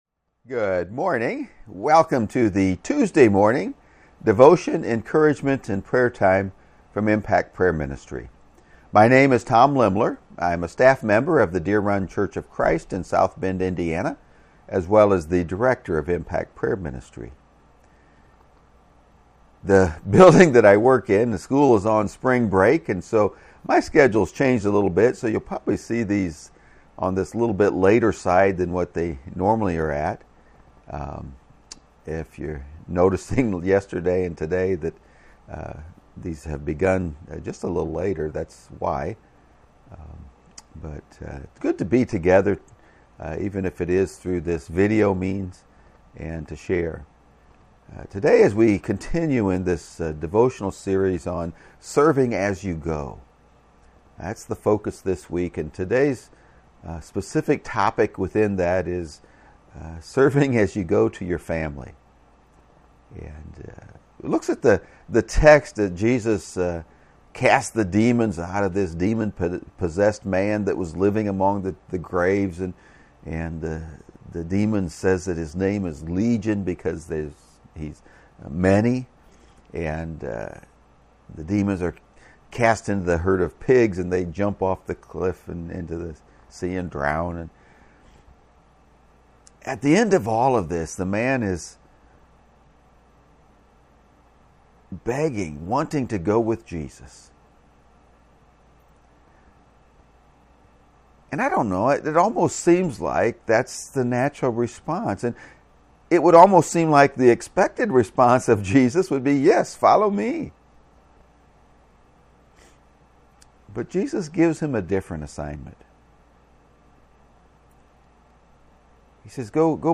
You can find the live video feeds of these encouragement and prayer times on Impact Prayer Ministry’s Facebook page and YouTube channel.